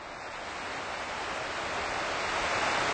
cf_rain_on_corrugated_iron_roof.ogg